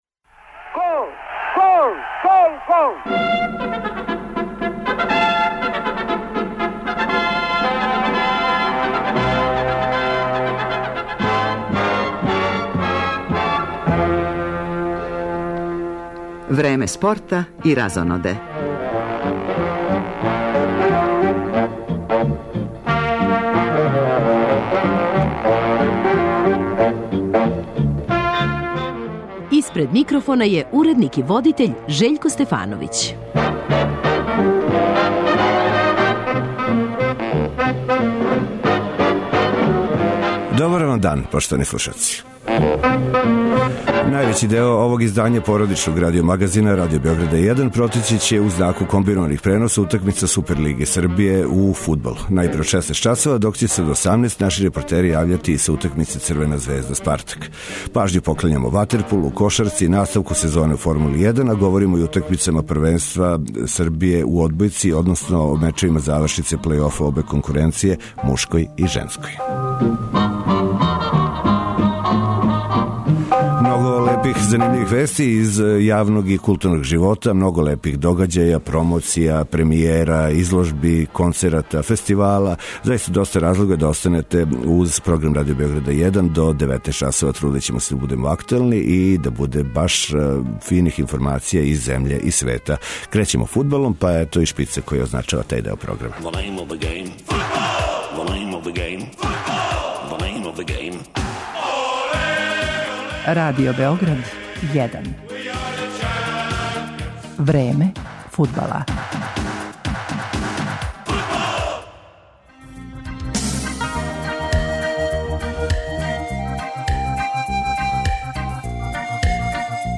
Највећи део овог издања породичног радио магазина Радио Београда 1 протећи ће у знаку комбинованих преноса утакмица Супер лиге Србије у фудбалу - најпре од 16 часова, док ће се од 18, наши репортери јављати са утакмице Црвена звезда - Спартак.